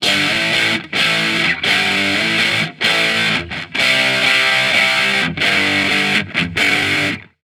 Guitar Licks 130BPM (12).wav